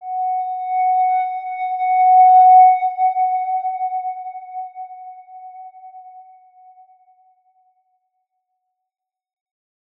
X_Windwistle-F#4-ff.wav